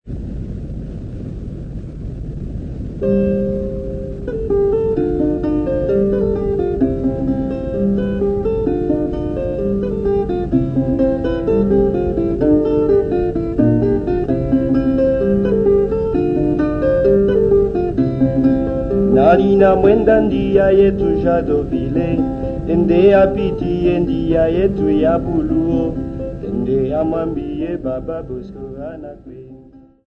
Folk music--Africa
Field recordings
Africa Democratic Republic of the Congo Jadotville f-cg
sound recording-musical
A topical song about an impression of things to come in the workd of African guitar music accompanied by guitar.
96000Hz 24Bit Stereo